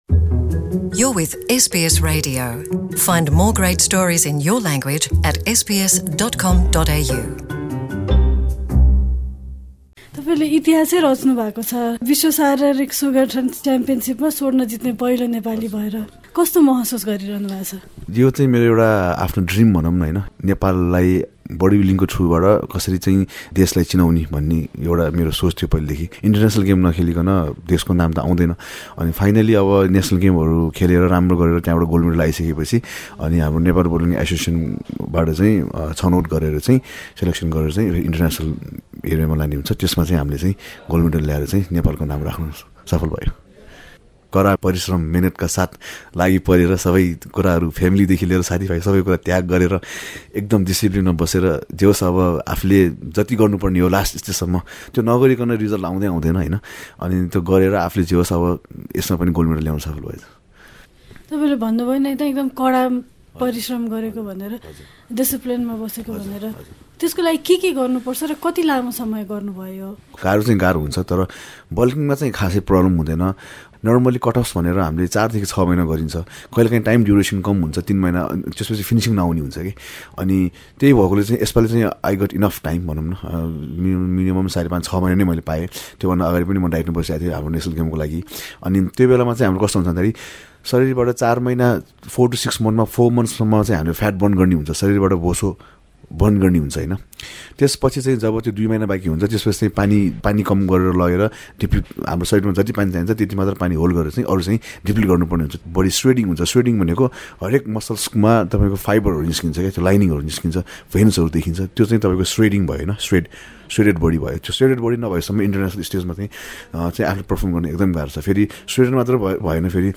हाम्रो कुराकानी